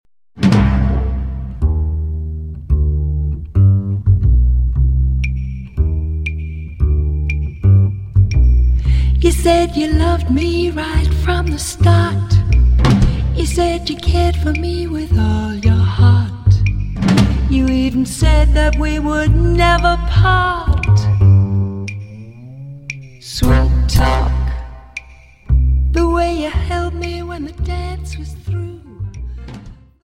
Dance: Slowfox